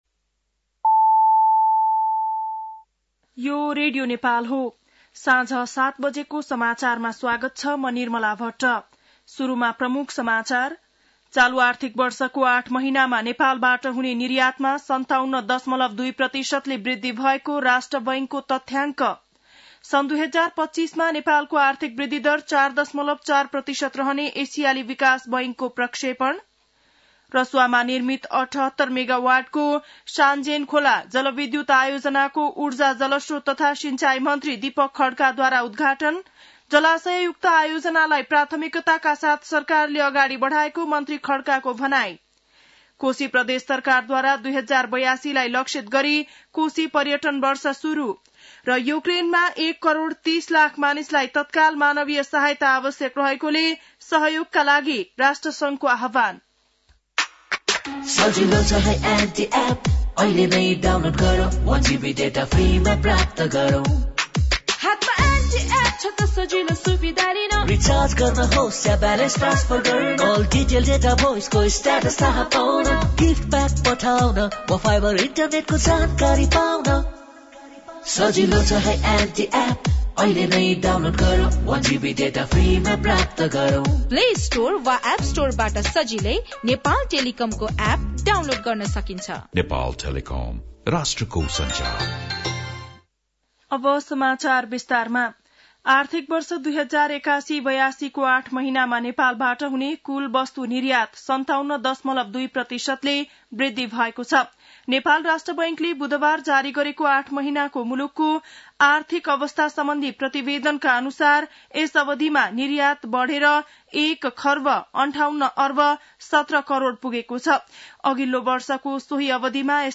बेलुकी ७ बजेको नेपाली समाचार : २७ चैत , २०८१